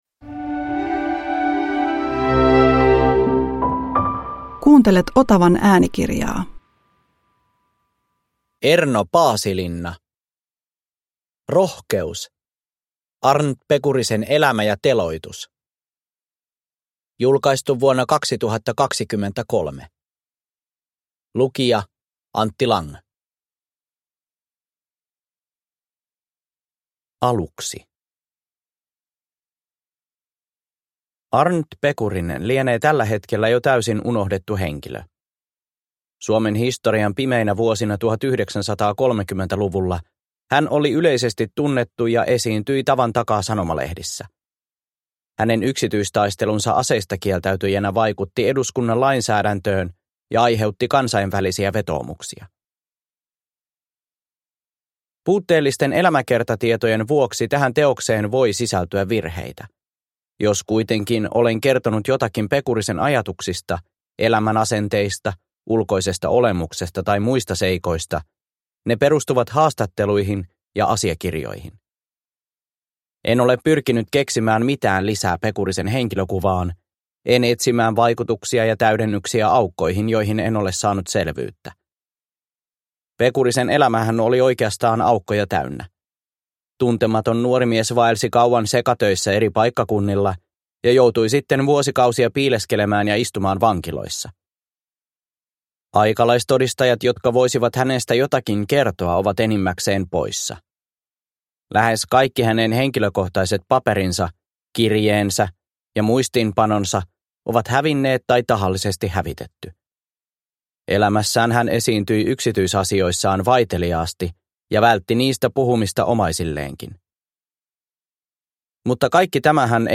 Rohkeus: Arndt Pekurisen elämä ja teloitus – Ljudbok – Laddas ner